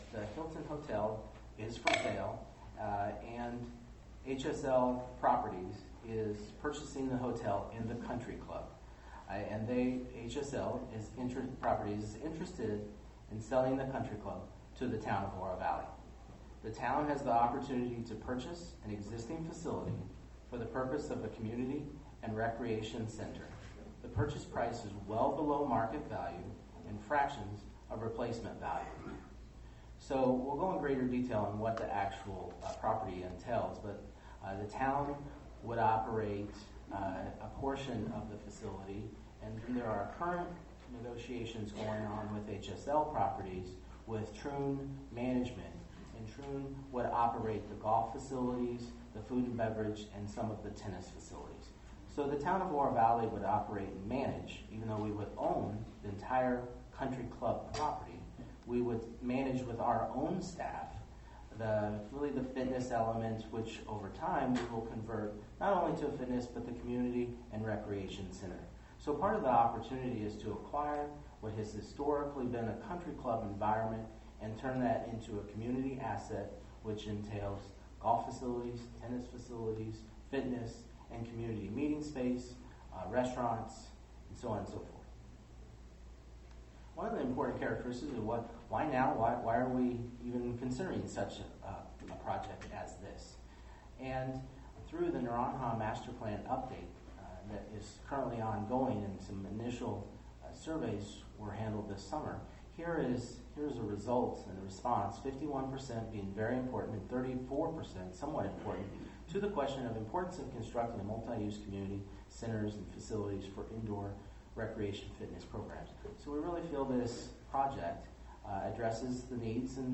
Town Manager Greg Caton review the basics of the country club deal with the Town Council Wednesday, Dec. 3.